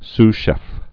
(sshĕf, s-shĕf)